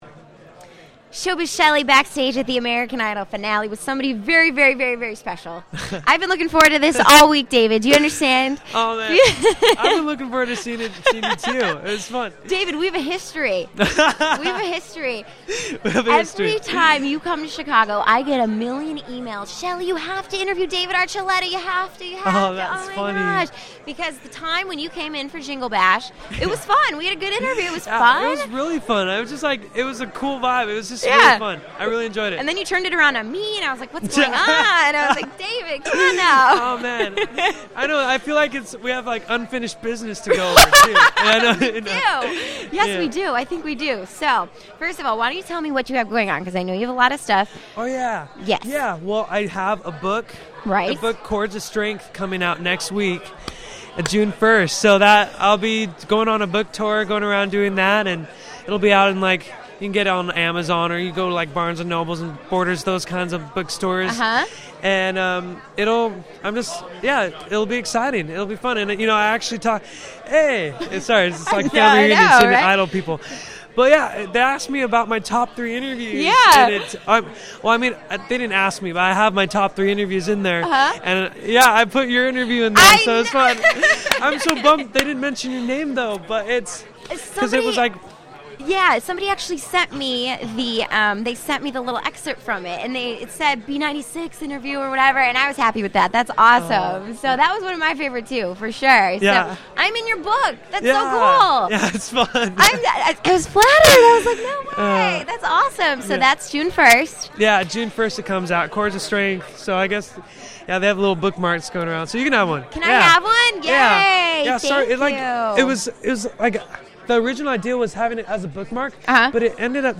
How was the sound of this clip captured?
Source: B96 Radio